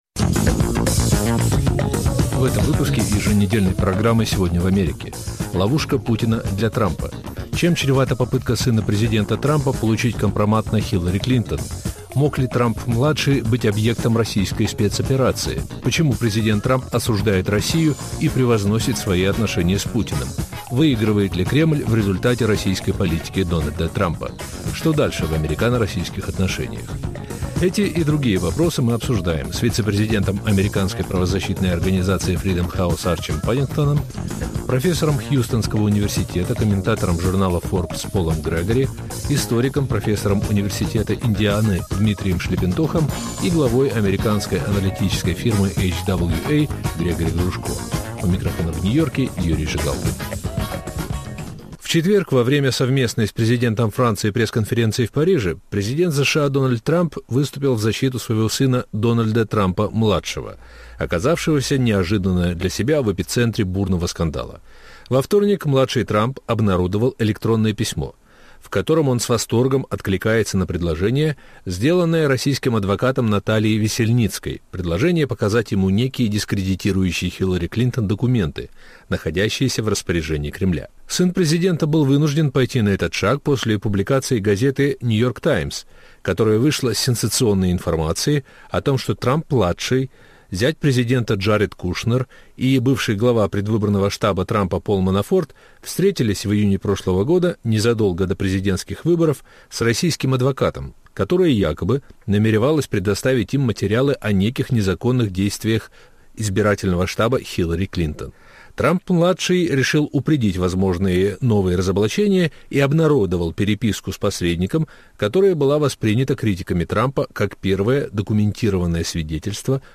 Обсуждают и спорят американские эксперты